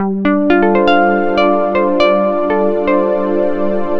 Jarre Movement A 120.wav